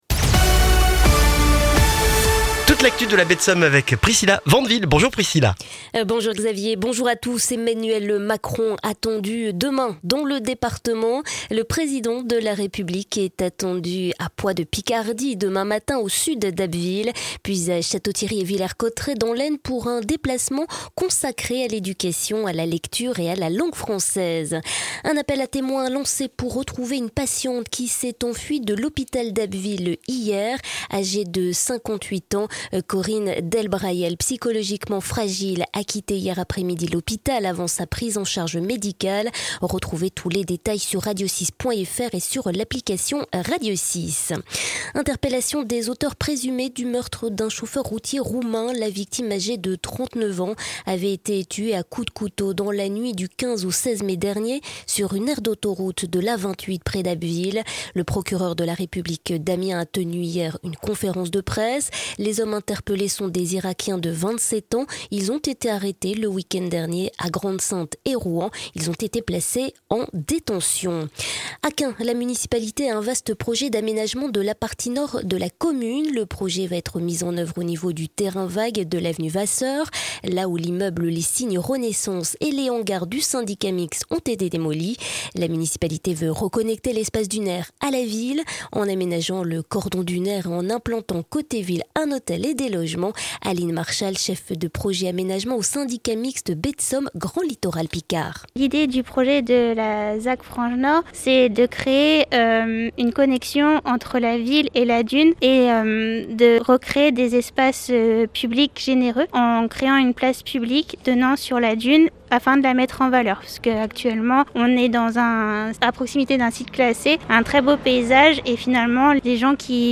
Le journal du mercredi 16 juin en Baie de Somme et dans la région d'Abbeville